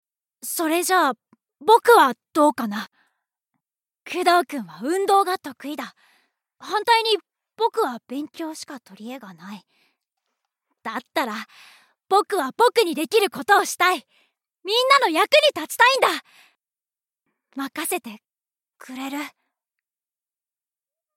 ボイスサンプル
理知的な少年